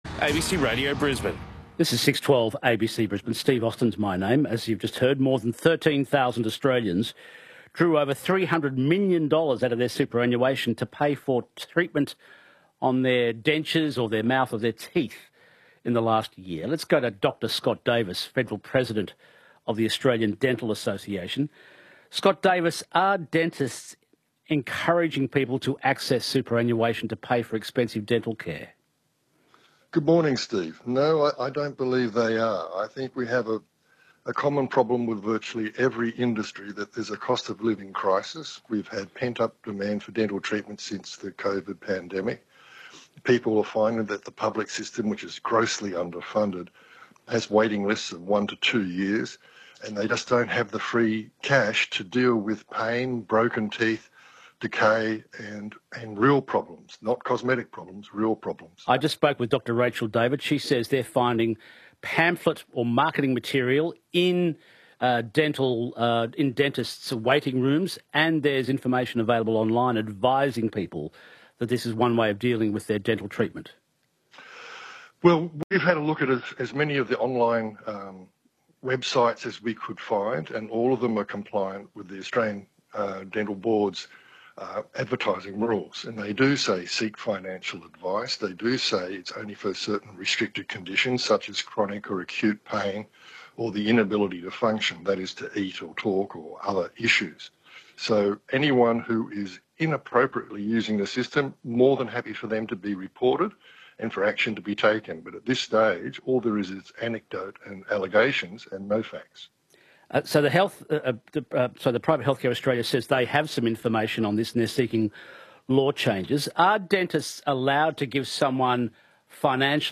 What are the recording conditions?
ABC Radio Brisbane interview